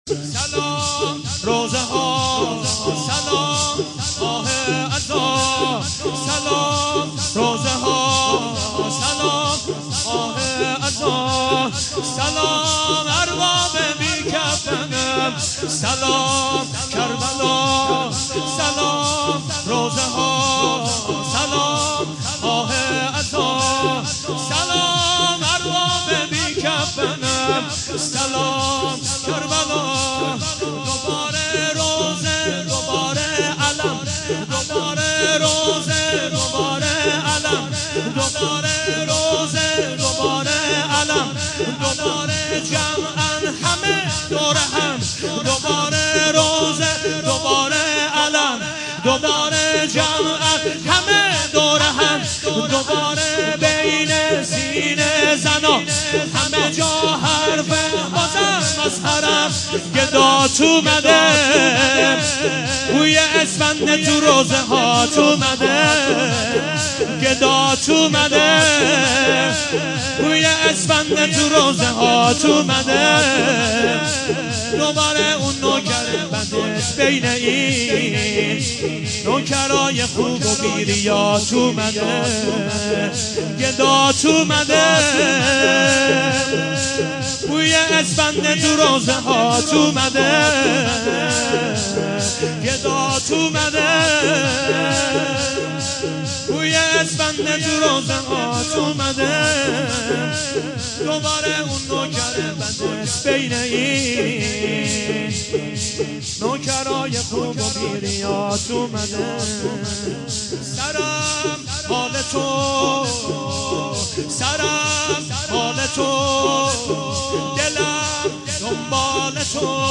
سلام روضه ها سلام ماه عزا - شور